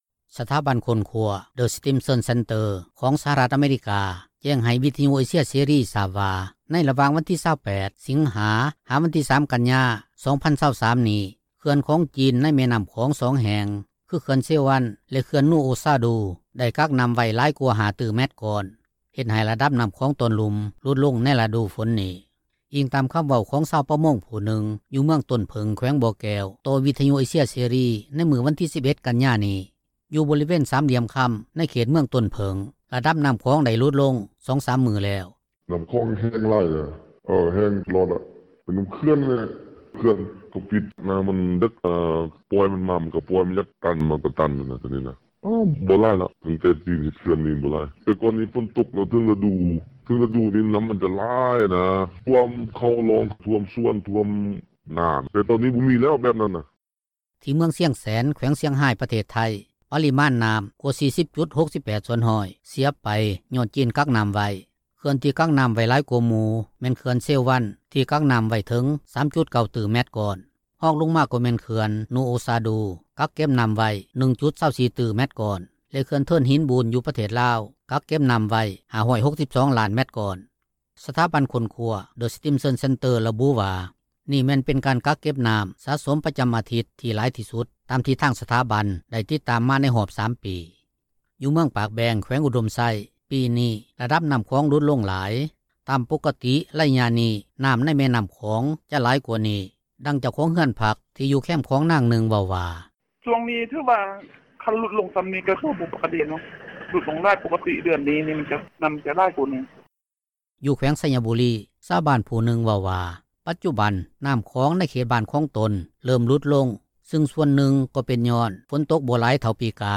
ດັ່ງເຈົ້າຂອງເຮືອນພັກ ທີ່ຢູ່ແຄມຂອງນາງນຶ່ງ ເວົ້າວ່າ: